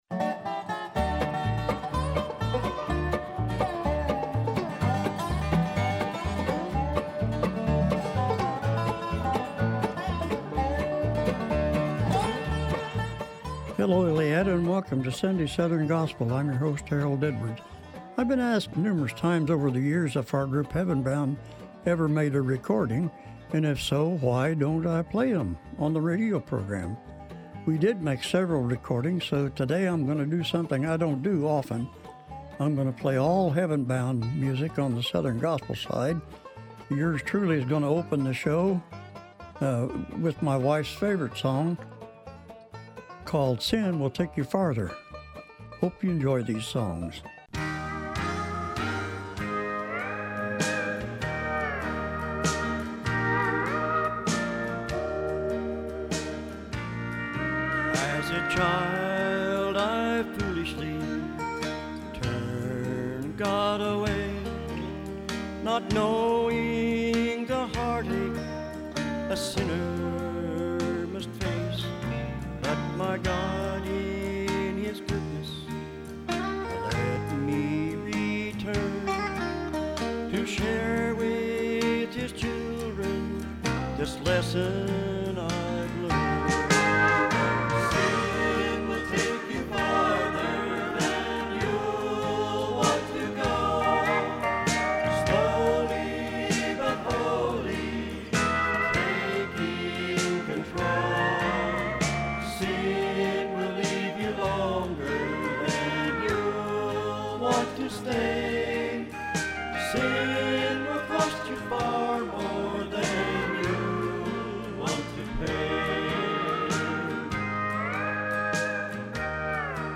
sunday-southern-gospel-11-9-25.mp3